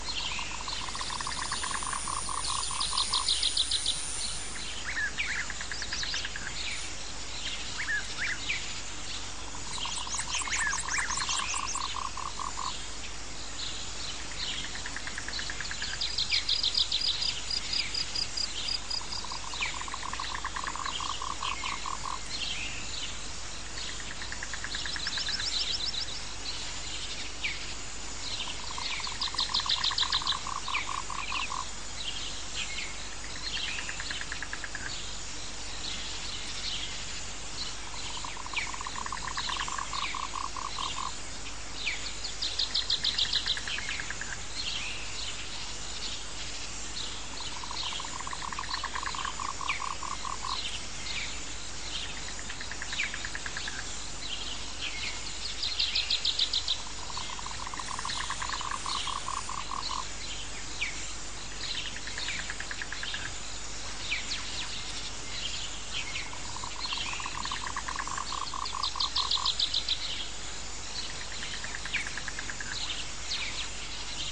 Hot Jungle Day (1 Hour)
Nature sounds are recorded & designed to help people sleep, allowing you to relax and enjoy the sounds of nature while you rest or focus, with no adverts or interruptions.
Perfect for their masking effects, they are also helpful for people suffering with tinnitus.
Hot-Jungle-Day-Sample.mp3